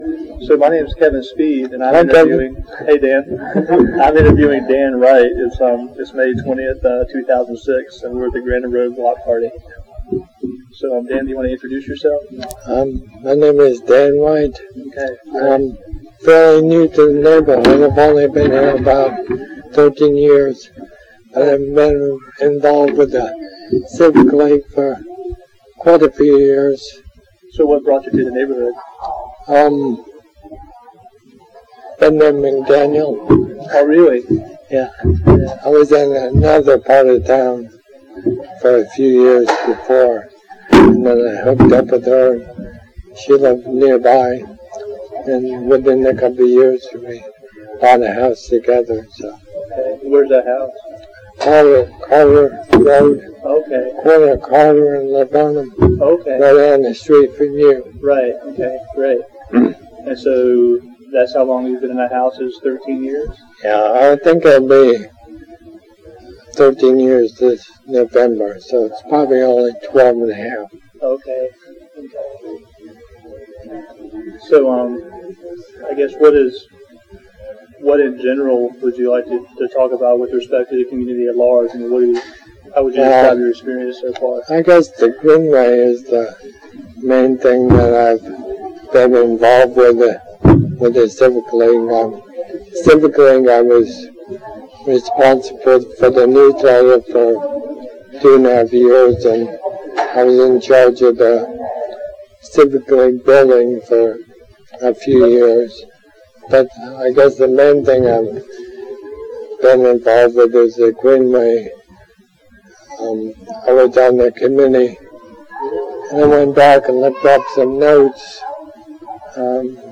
Neighborhood History Interview
Location: Greater Raleigh Court Civic League Block Party